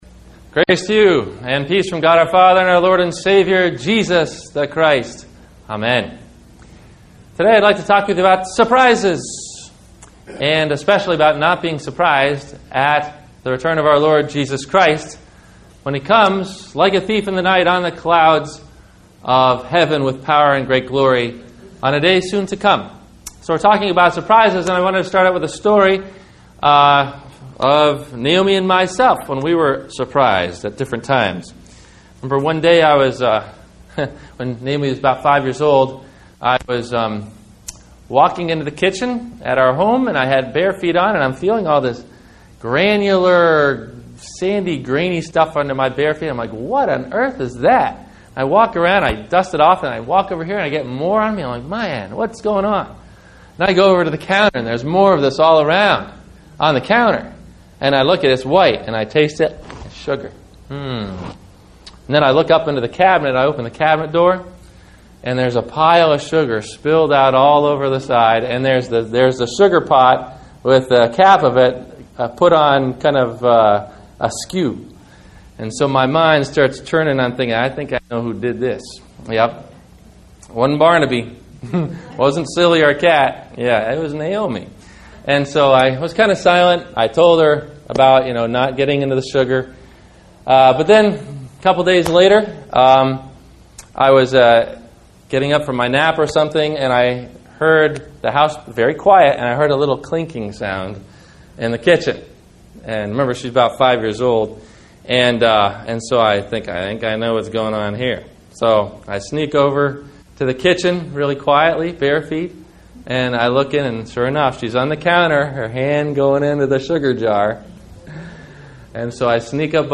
Advent Week 1 - Sermon - December 02 2009 - Christ Lutheran Cape Canaveral